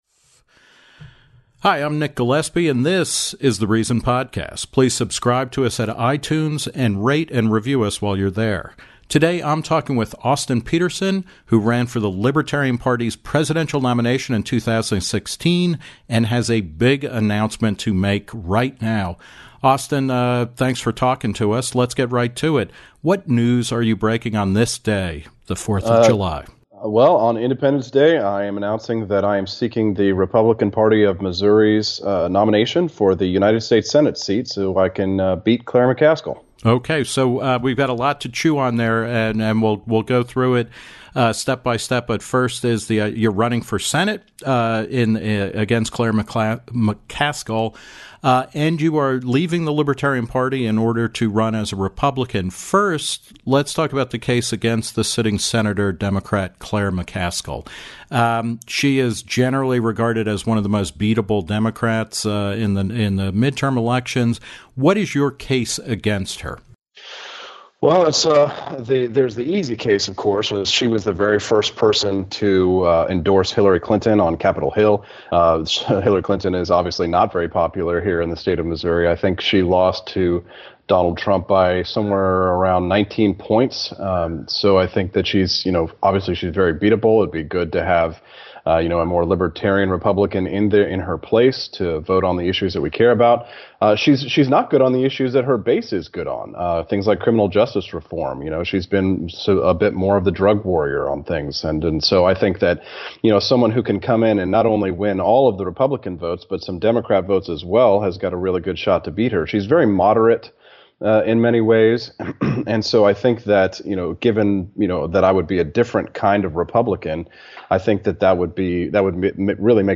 In an exclusive interview with Reason